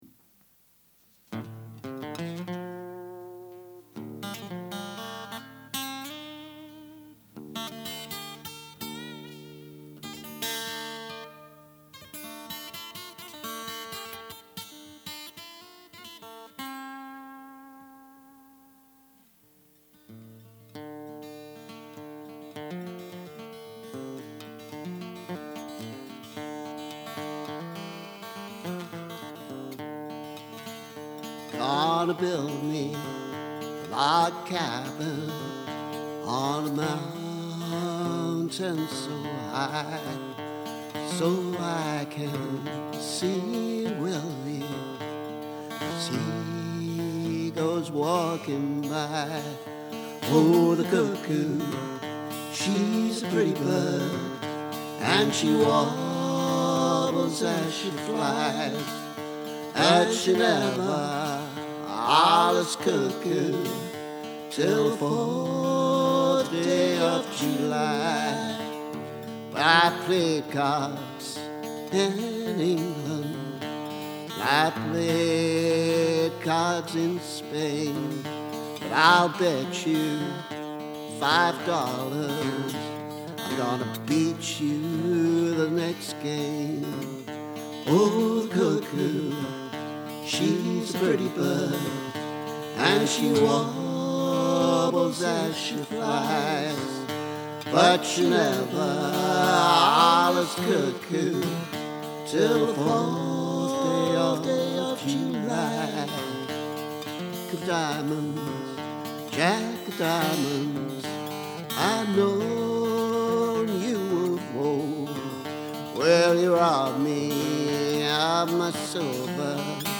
Cuckoo: with Taylor amplified. Middle break is too long.